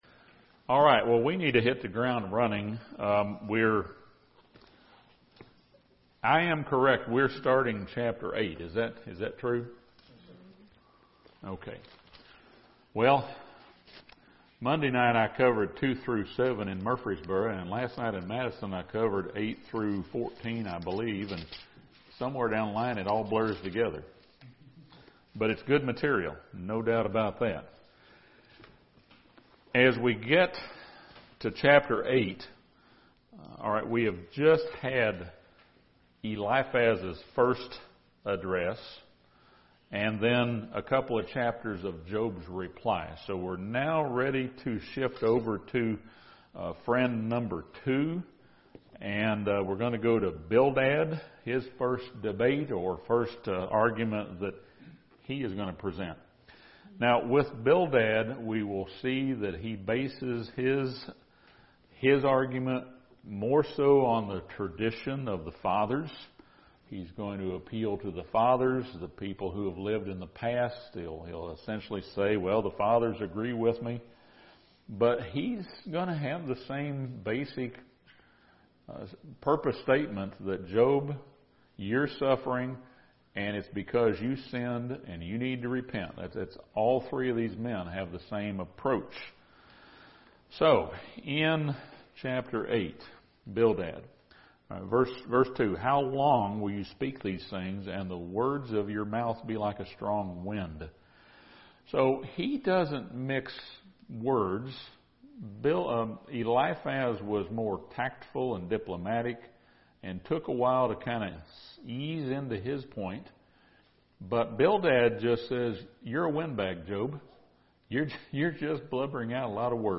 This Bible Study surveys chapters 8-14 of Job.